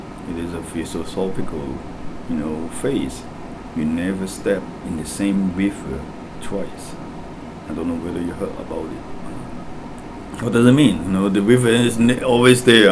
S1 = Hong Kong male S2 = Malaysian female Context : S1 is discussing why he finds the sunset every day the most beautiful thing he has ever seen.
Intended Words : phrase , river Heard as : phase , mirror
There is no [r] in phrase .